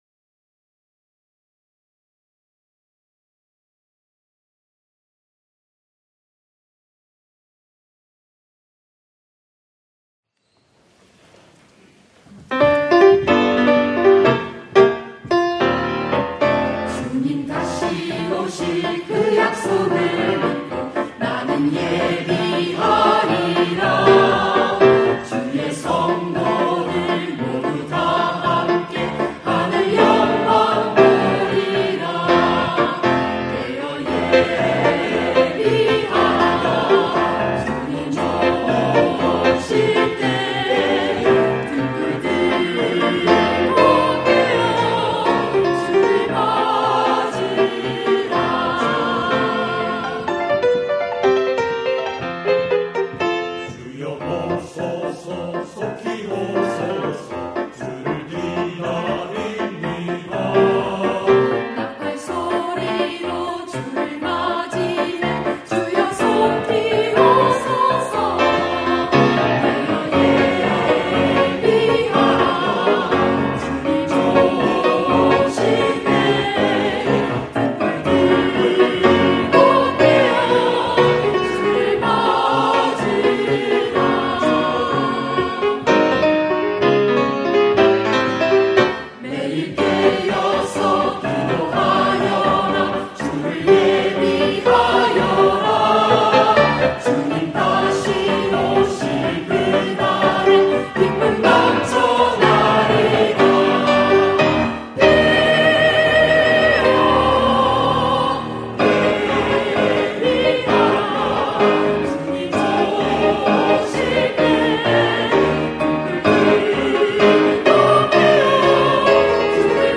주를 예비하라 > 찬양영상